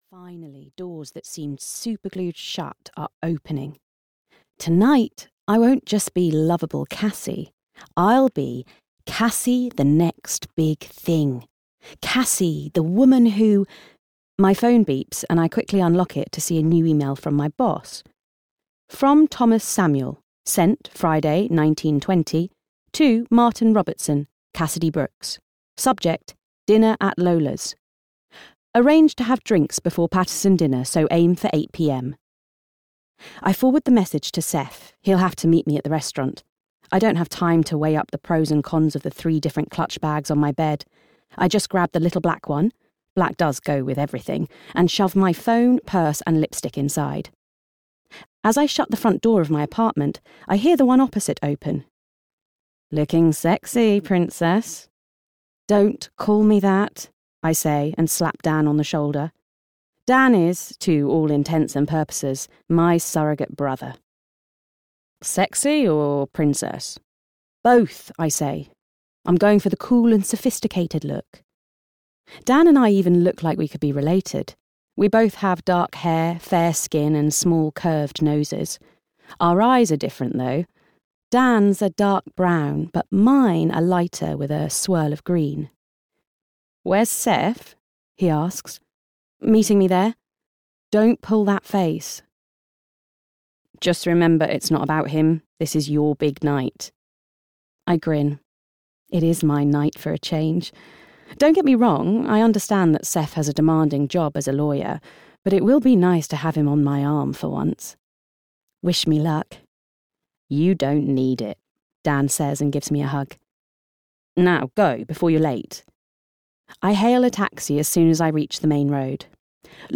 Puzzle Girl (EN) audiokniha
Ukázka z knihy